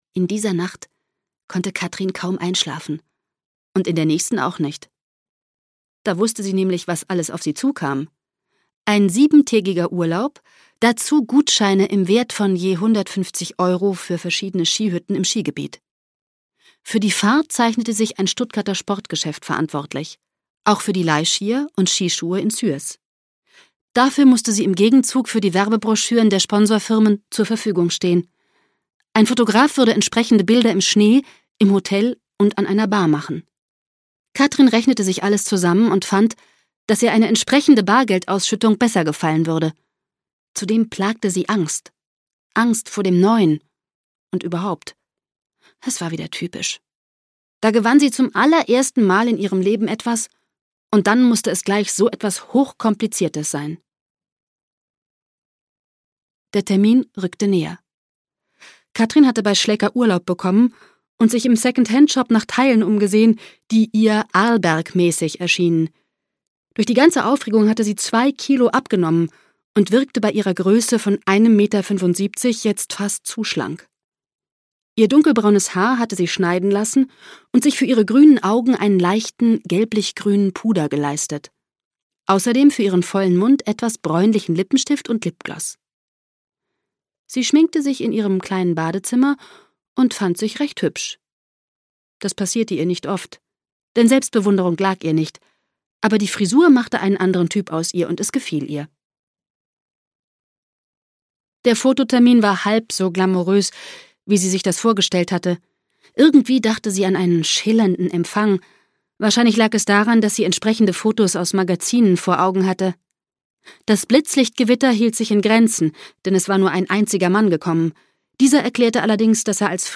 Fünf-Sterne-Kerle inklusive - Gaby Hauptmann - Hörbuch